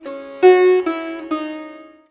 SITAR LINE22.wav